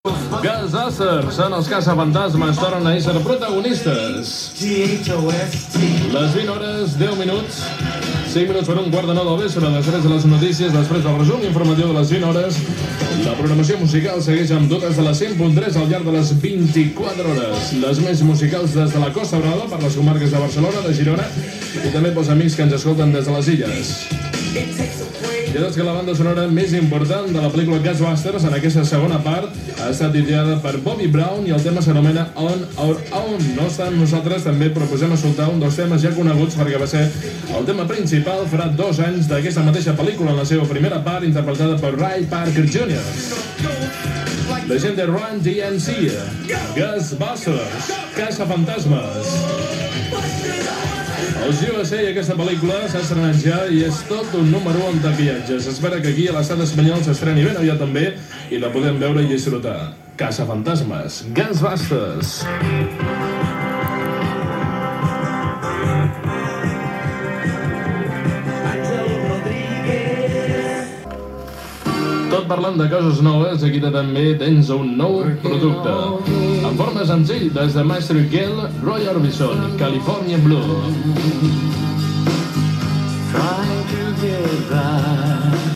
Hora i presentació de la fórmula musical Gènere radiofònic Musical